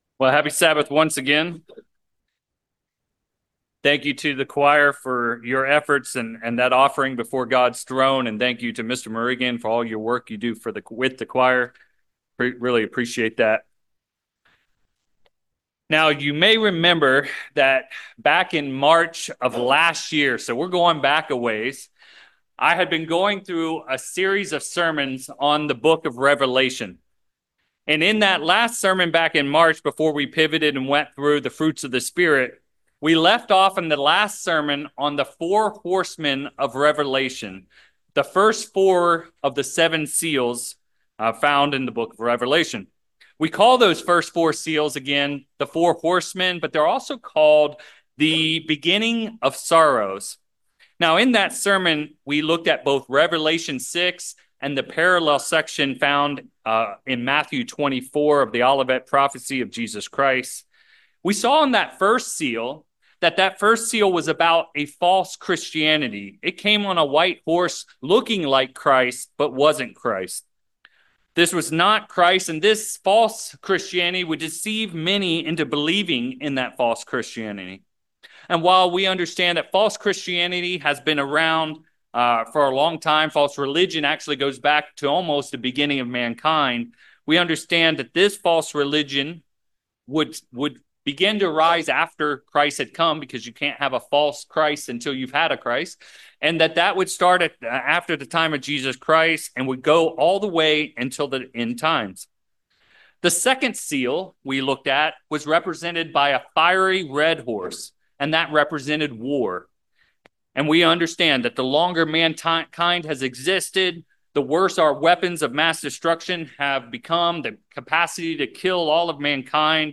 In this sermon, we dive into this incredibly important scripture to understand what Jesus Christ wanted us to know and be looking for.
Sermons